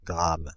Ääntäminen
Ääntäminen France (Île-de-France): IPA: /ɡʁam/ Tuntematon aksentti: IPA: /gʁam/ Haettu sana löytyi näillä lähdekielillä: ranska Käännöksiä ei löytynyt valitulle kohdekielelle.